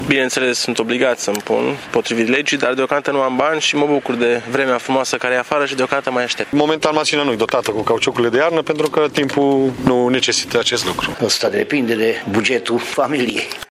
Târgumureșenii spun că profită de vremea bună și amână cât mai mult cheltuielile cauzate de schimbul de anvelope, care pot depăși 1.000 de lei: